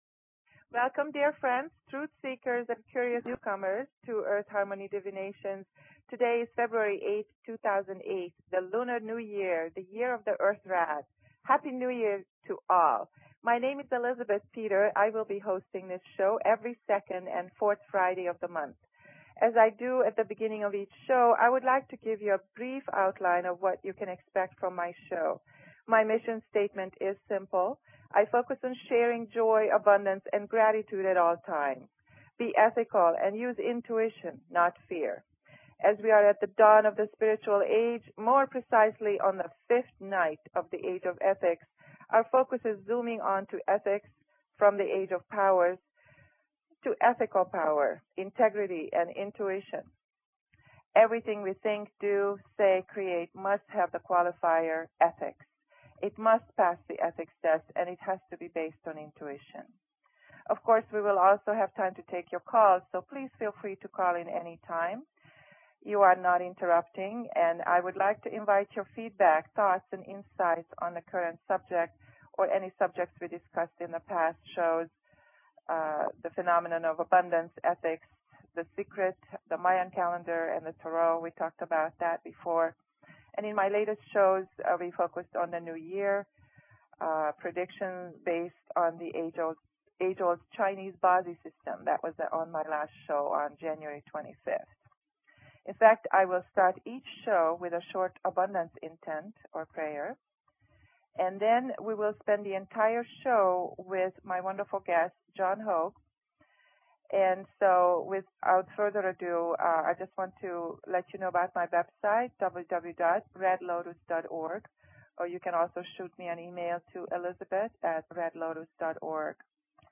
Talk Show Episode, Audio Podcast, Earth_Harmony_Divinations and Courtesy of BBS Radio on , show guests , about , categorized as